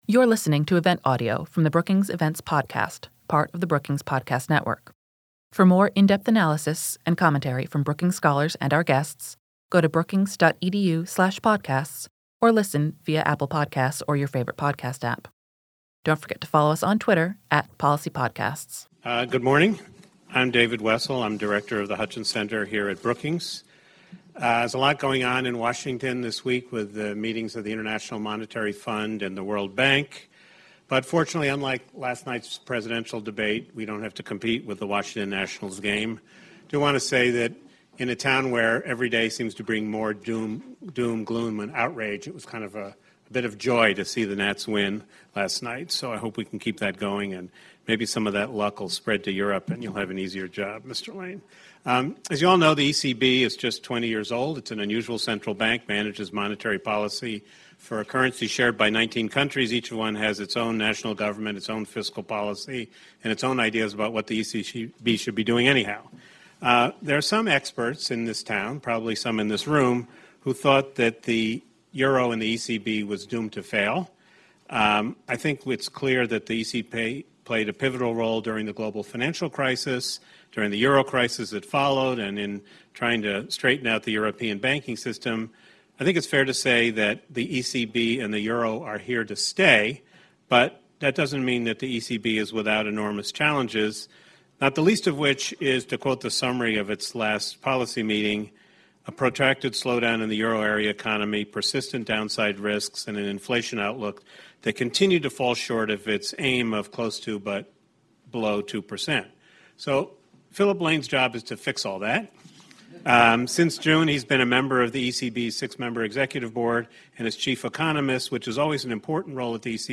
On October 16 the Hutchins Center on Fiscal & Monetary Policy hosted Philip Lane, a member of the ECB’s Executive Board and its chief economist.
Discussion